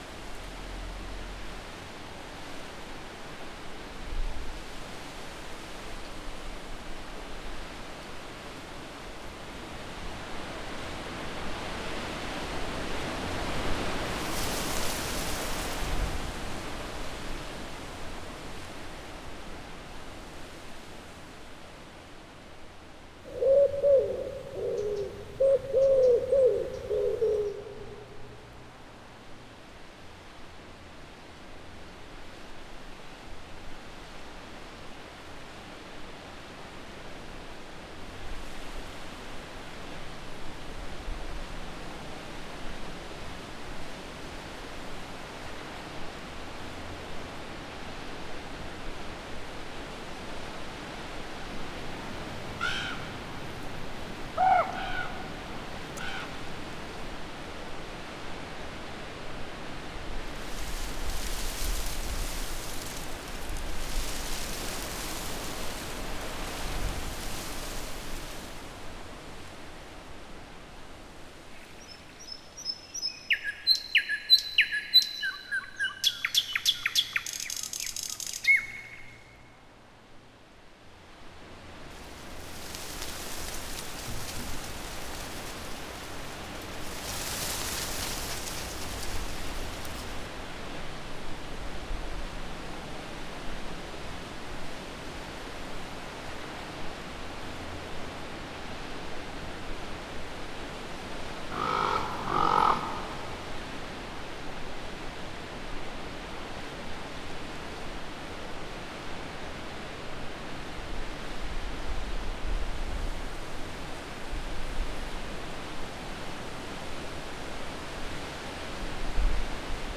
Farm_Atmos_05.wav